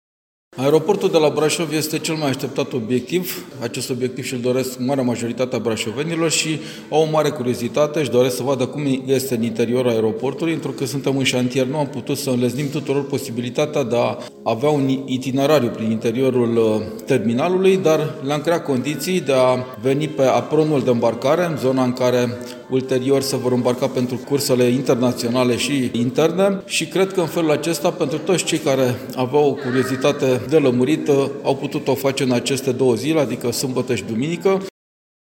Mulți dintre ei au ales această variantă și din curiozitatea de a vedea terminalul Aeroportului, a declarat unul dintre voluntarii campaniei de vaccinare, președintele Consiliului Județean Brașov, Adrian Veștea: